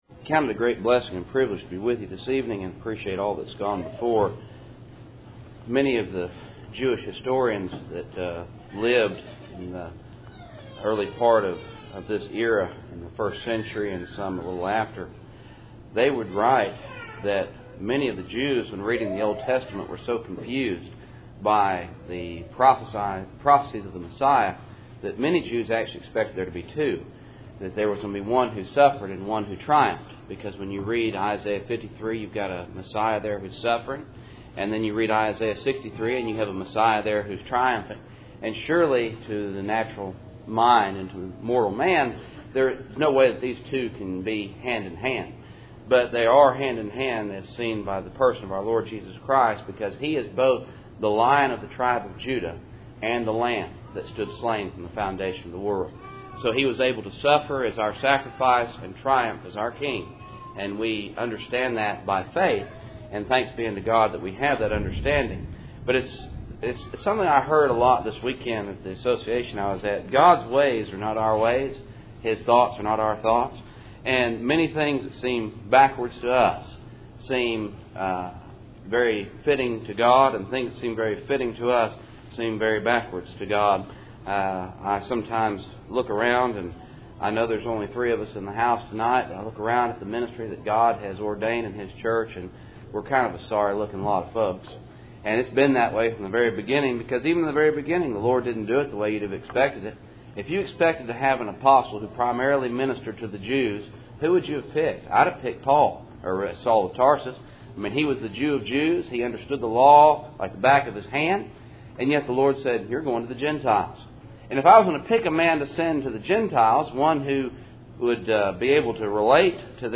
2 Peter 1:16-21 Service Type: Cool Springs PBC Sunday Evening %todo_render% « II Peter 1:12-15